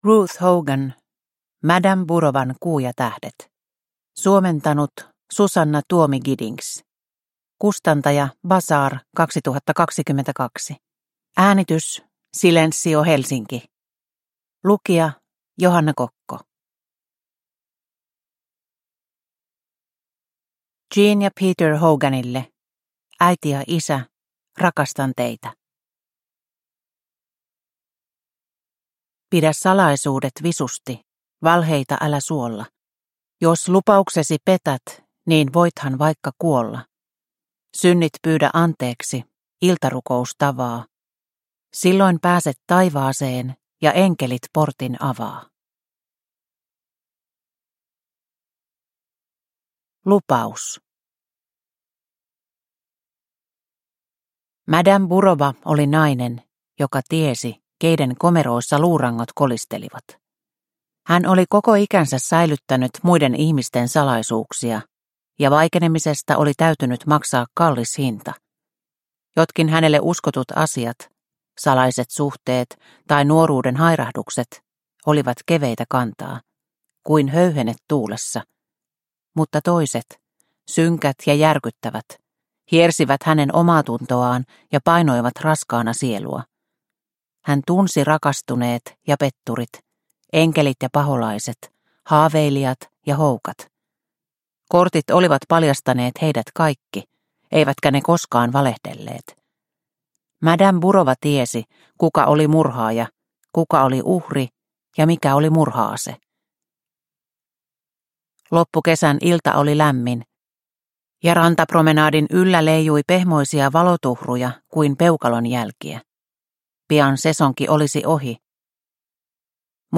Madame Burovan kuu ja tähdet – Ljudbok – Laddas ner